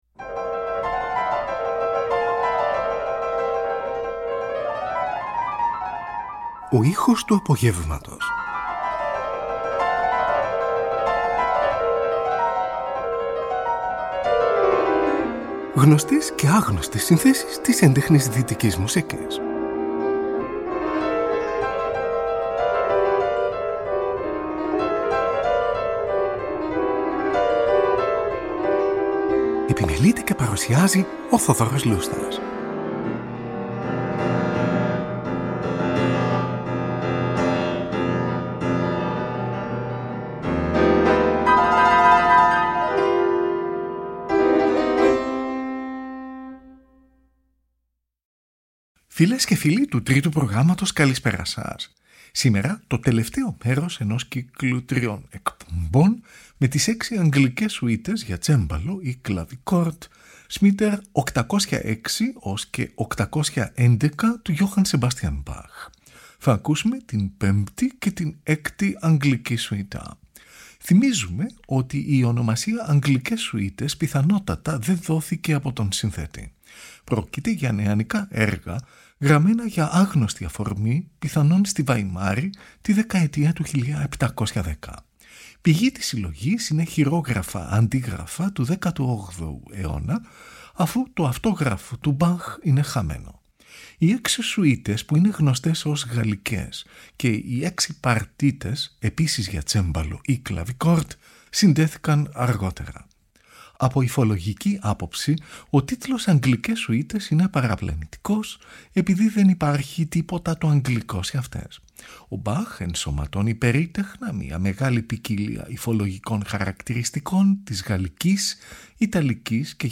από δισκογραφικές εργασίες το 1978 και το 1962
Σουιτα για Πιανο Αρ. 5 σε Μι Ελασσονα
Σουιτα για Πιανο Αρ. 6 σε Φα Μειζονα